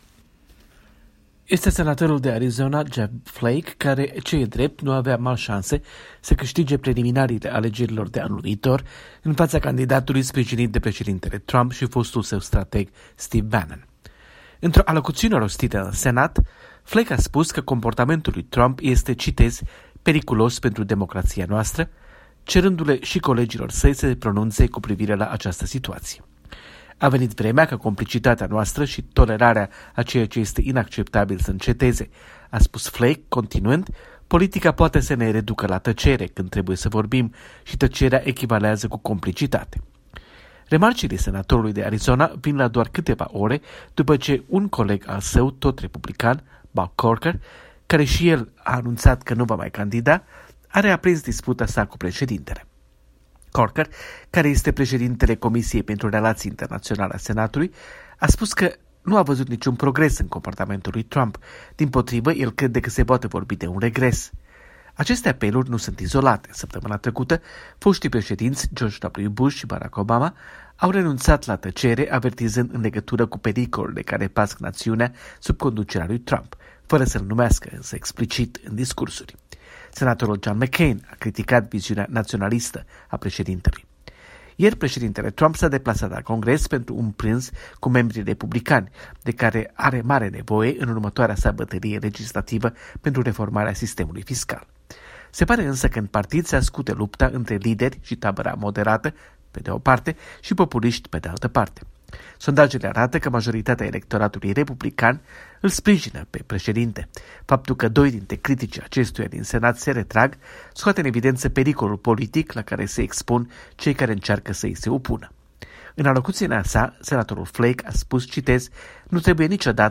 Corespondența zilei de la Washington.